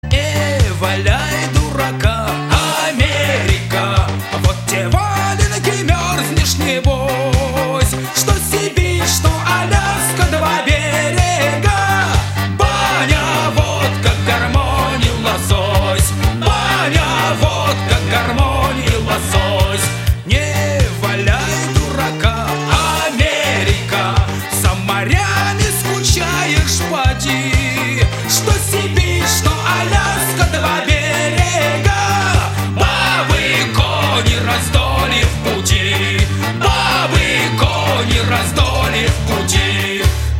патриотические
застольные
эстрадные